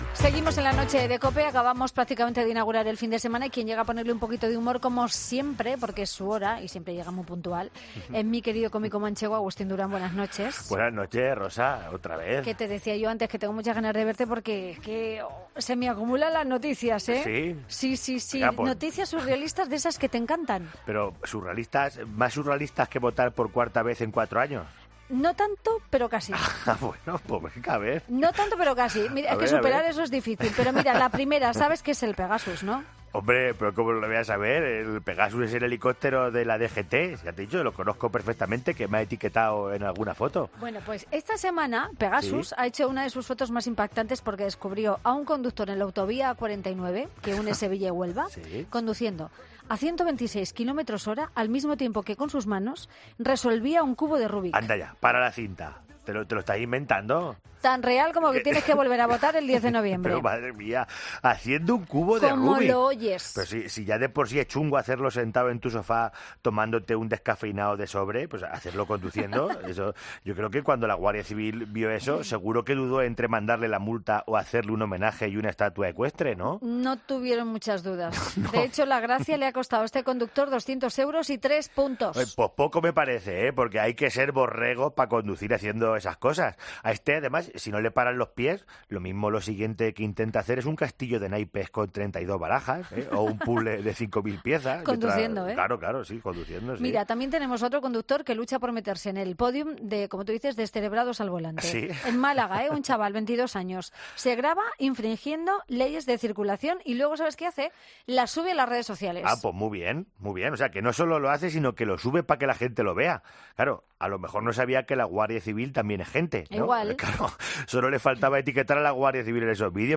Redacción digital Madrid - Publicado el 21 sep 2019, 01:21 - Actualizado 21 sep 2019, 01:32 1 min lectura Descargar Facebook Twitter Whatsapp Telegram Enviar por email Copiar enlace Nuestro cómico manchego está cansado de cerrar sobre y meter papeleta, tiene un mensaje para la situación política actual pero siempre con música y mucho, mucho humor.